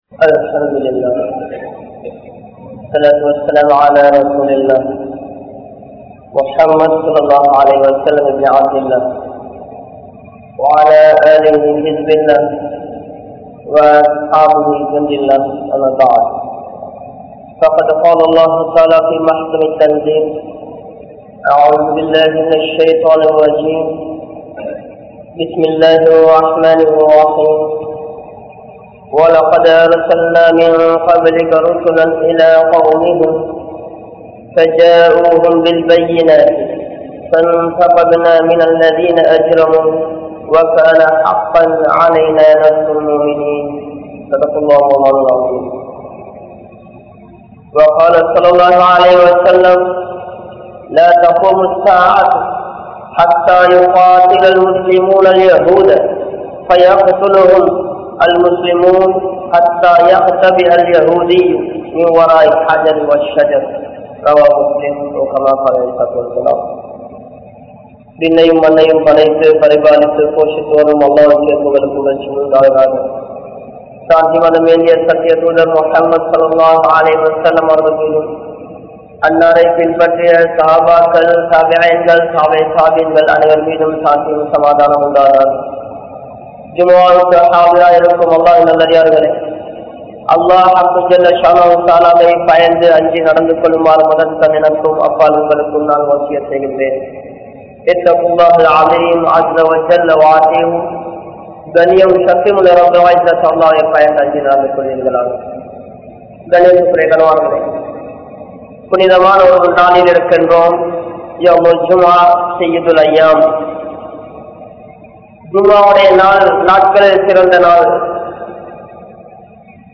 Muslim Samoohaththai Kaattik Kodukatheerhal(முஸ்லிம் சமூகத்தை காட்டிக் கொடுக்காதீர்கள்) | Audio Bayans | All Ceylon Muslim Youth Community | Addalaichenai
Colombo 09, Minnan Jumua Masjith